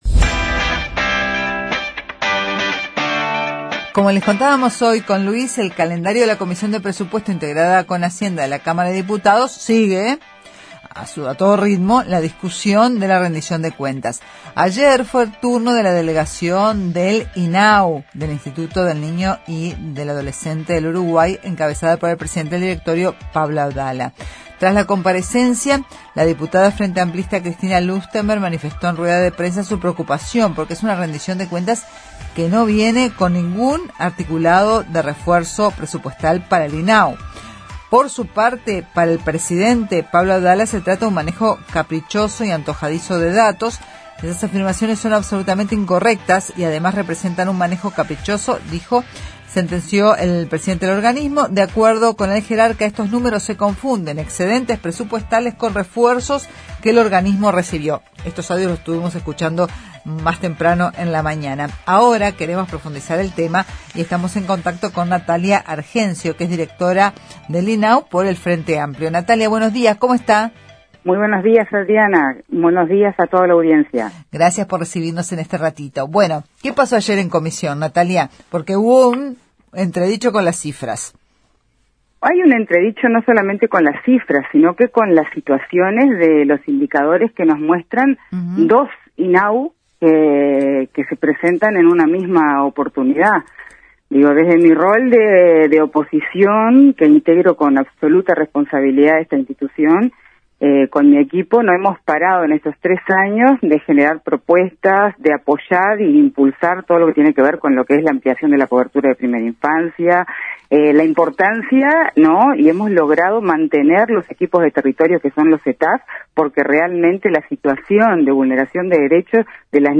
Tras la comparecencia del Inau ayer en comisión de presupuesto integrada con hacienda que estudia el proyecto de rendición de cuentas, Natalia Argenzio, directora del Inau por el Frente Amplio dijo a Informativo Uruguay que se mostraron “2 Inau diferentes”.